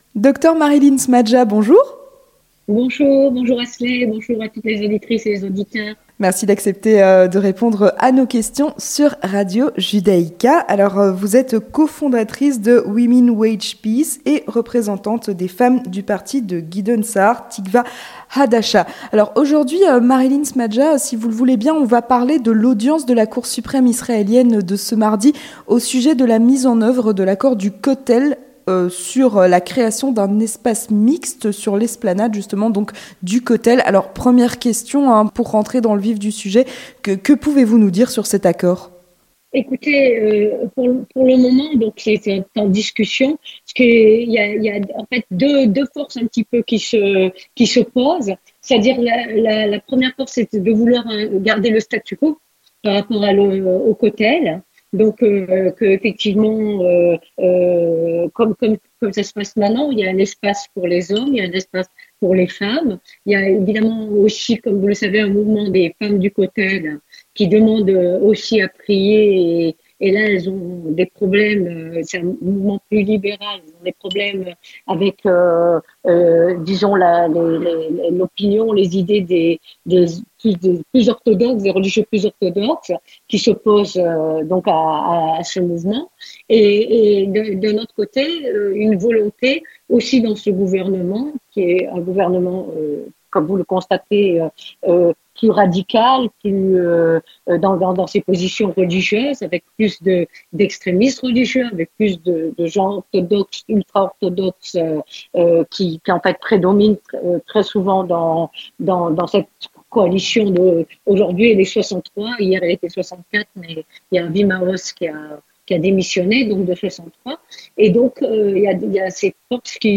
L'entretien du 18h